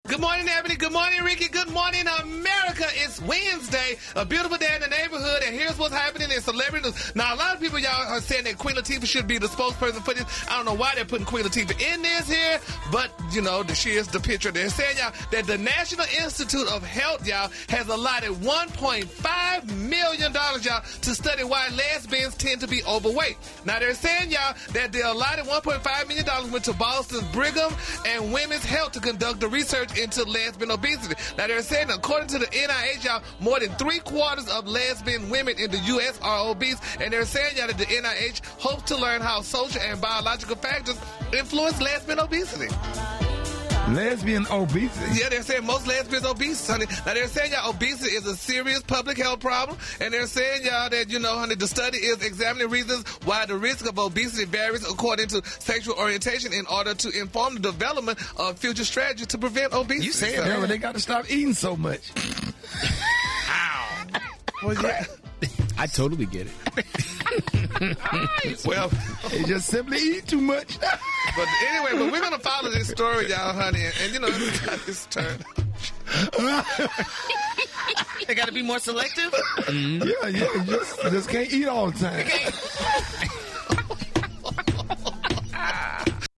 The Rickey Smiley Morning Show. Of course Rickey and the crew had jokes but it is a real story. According to The Washington Post, The National Institute of Health is spending 1.5 million to find out why 75 percent of lesbian women are obese and gay men are not.